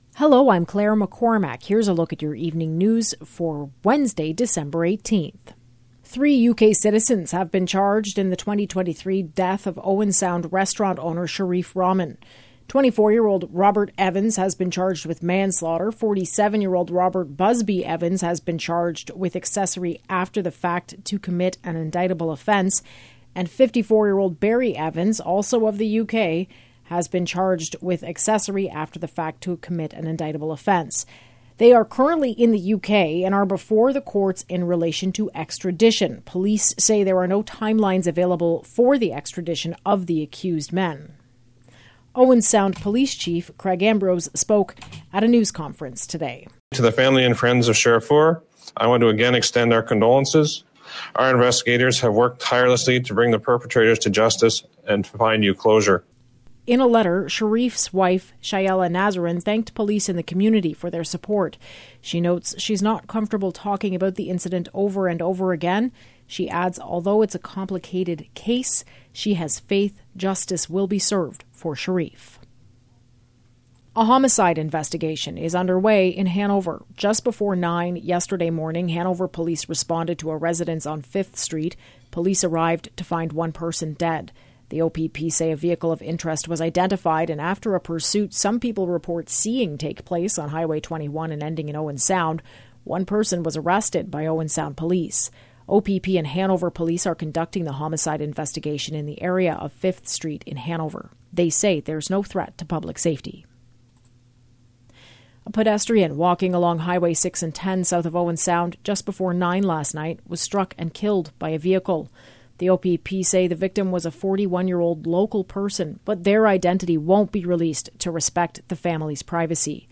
Evening News – Wednesday, December 18